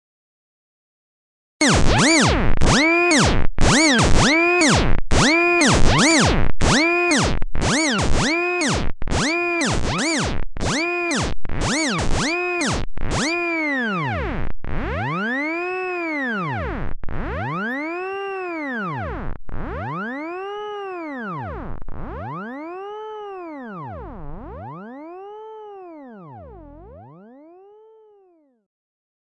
描述：奇怪的合成器失真东西
Tag: 停止 响亮的 机械的 尖叫 警报器 恼人的 划痕 混合器 搅拌机 疯狂的 扭曲的 停止 反向